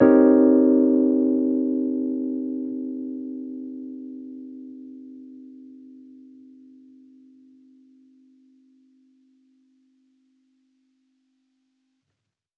Index of /musicradar/jazz-keys-samples/Chord Hits/Electric Piano 1
JK_ElPiano1_Chord-Cm7b9.wav